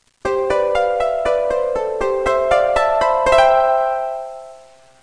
HARP.mp3